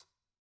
Conga-Tap1_v1_rr2_Sum.wav